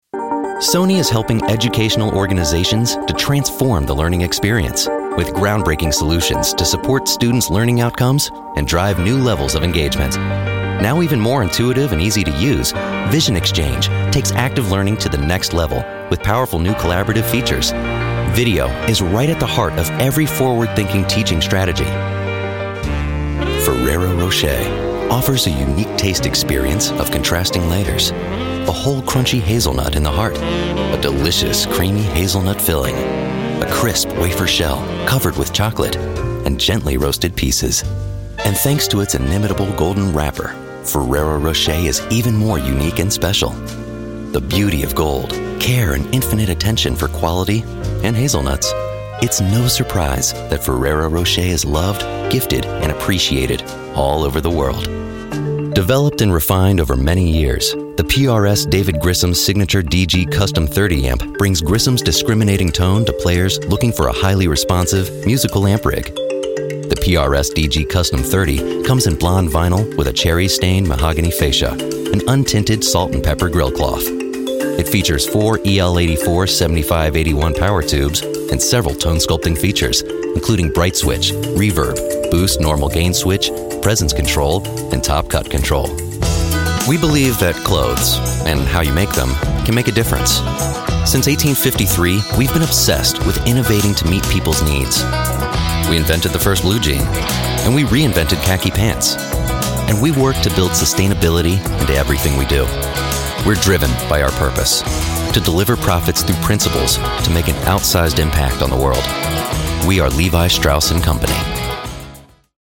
Smart, funny, versatile baritone suitable for character, corporate, and commercial work. Natural, smooth, sonorous, and warm delivery.
englisch (us)
Sprechprobe: Industrie (Muttersprache):